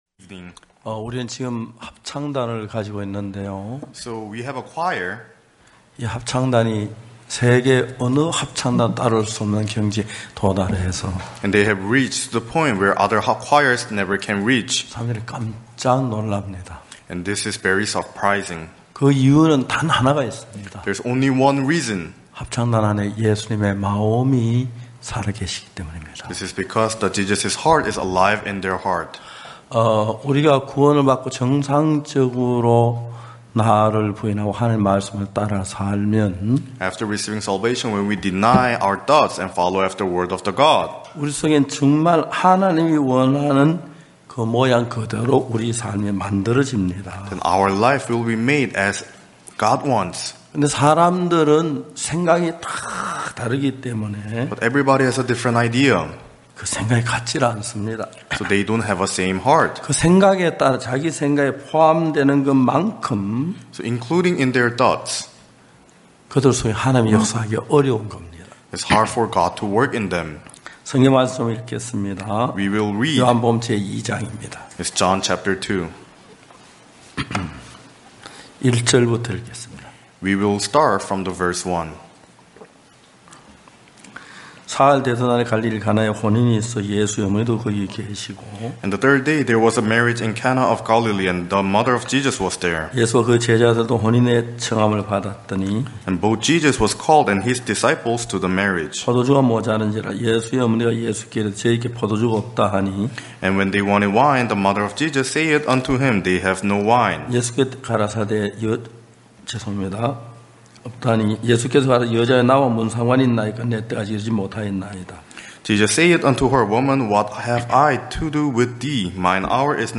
전국 각 지역의 성도들이 모여 함께 말씀을 듣고 교제를 나누는 연합예배.